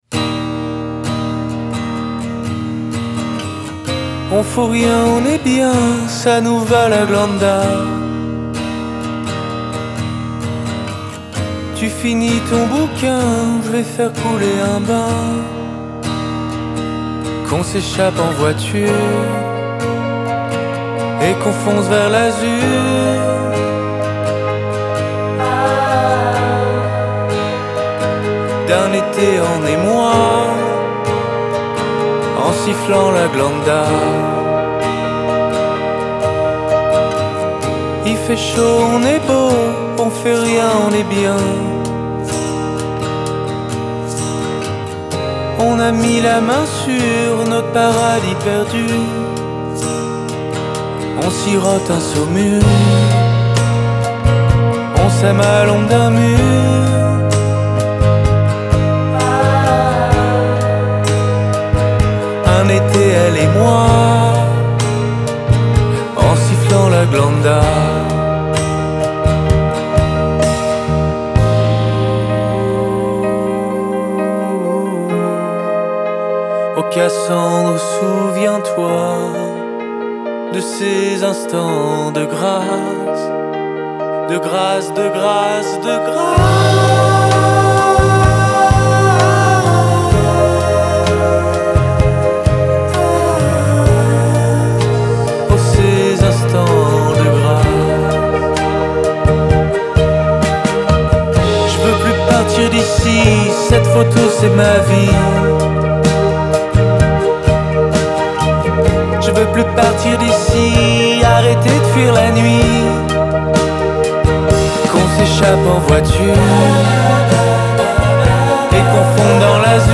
Genre: Alternative, French Pop